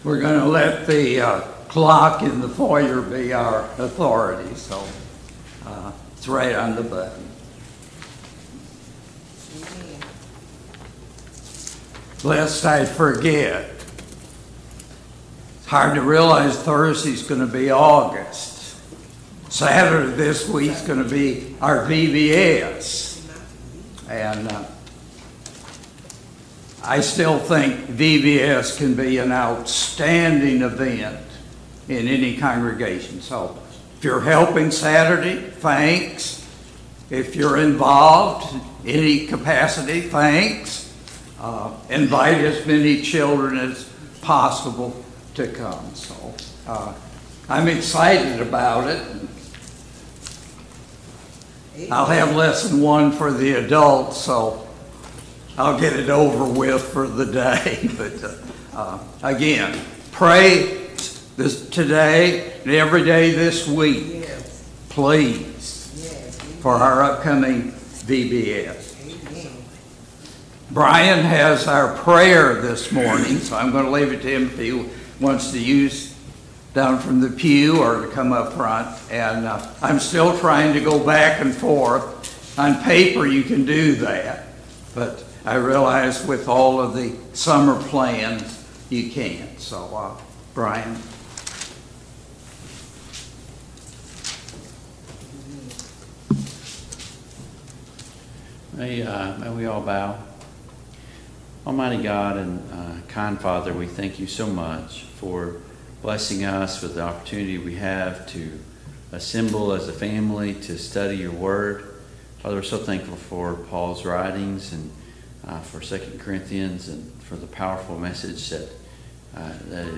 2 Corinthians 9 Service Type: Sunday Morning Bible Class Topics: Giving , Giving in Worship « 17.